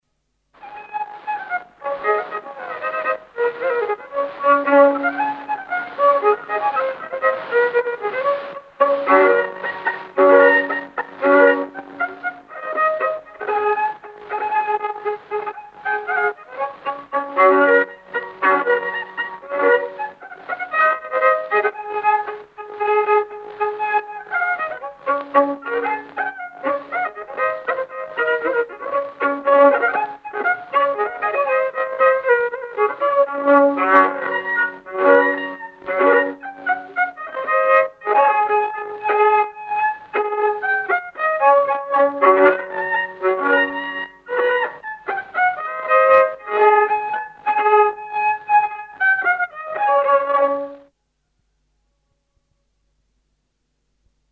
Polka "Käi ruttu sa"